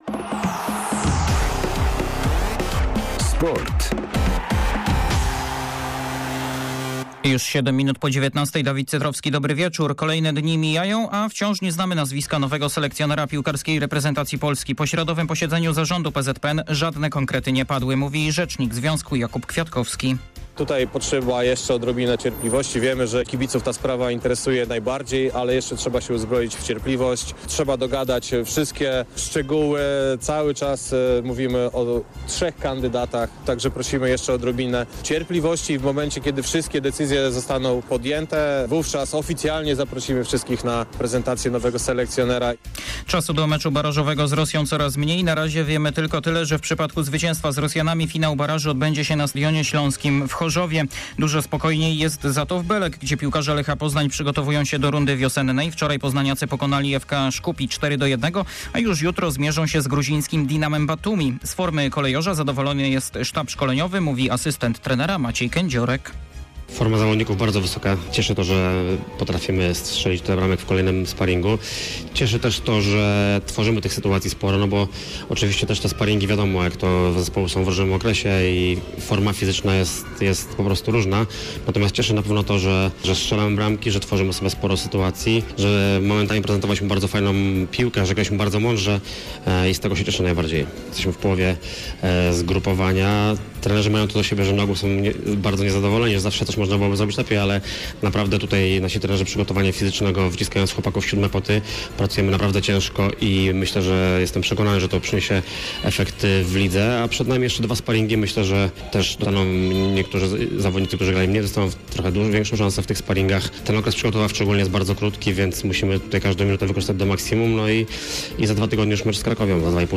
19.01.2022 SERWIS SPORTOWY GODZ. 19:05